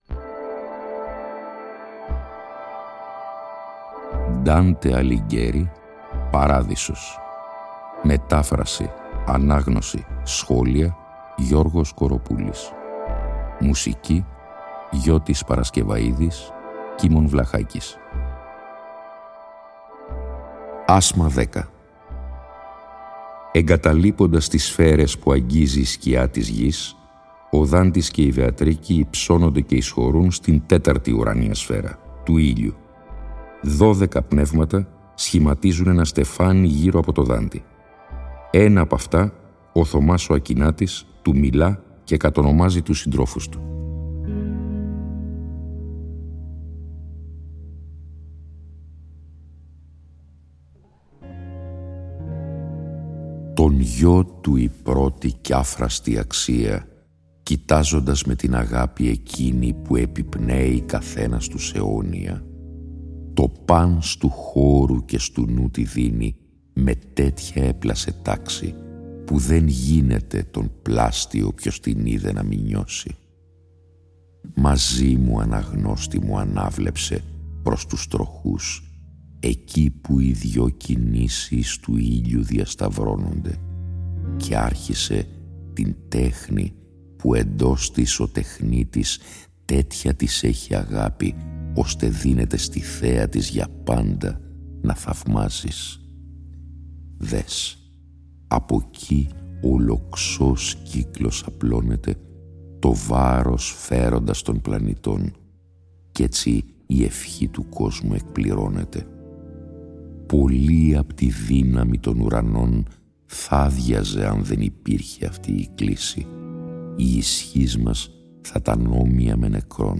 Η ανάγνωση των 33 ασμάτων του «Παραδείσου», σε 20 ημίωρα επεισόδια, (συνέχεια της ανάγνωσης του «Καθαρτηρίου», που είχε προηγηθεί) συνυφαίνεται και πάλι με μουσική